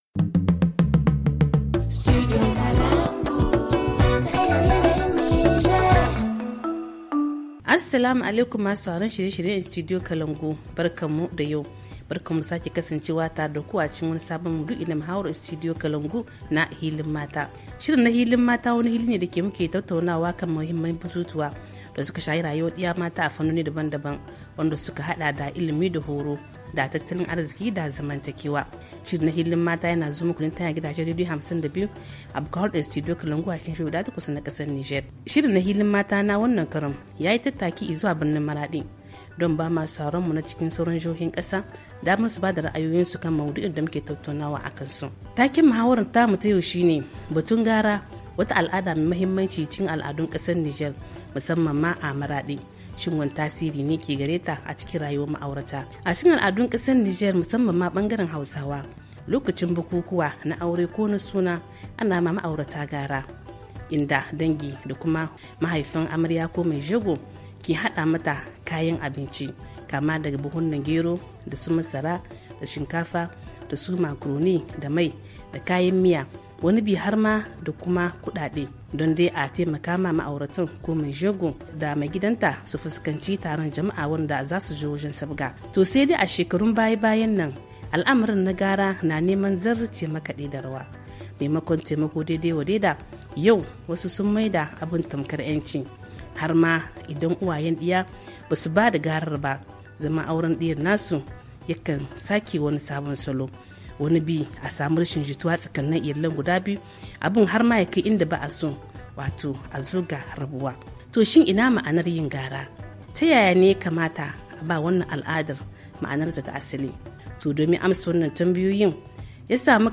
HA Le forum en haoussa Télécharger le forum ici.